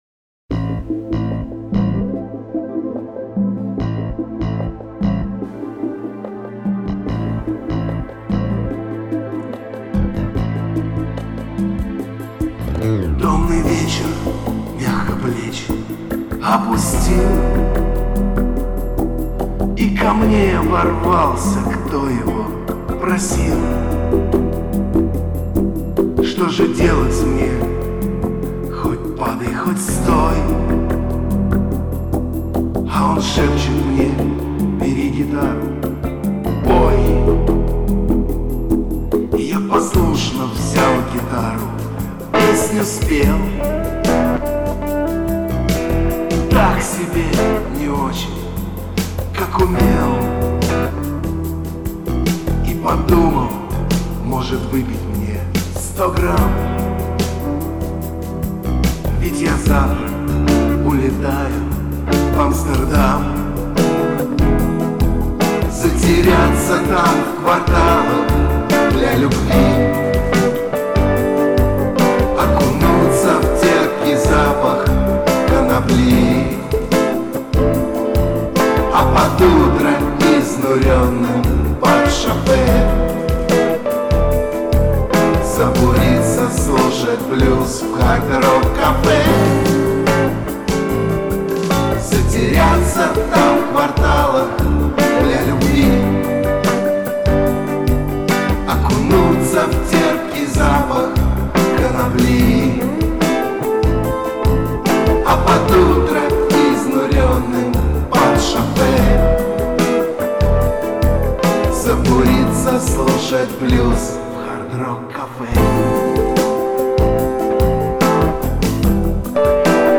Инди рок